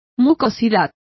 Complete with pronunciation of the translation of mucus.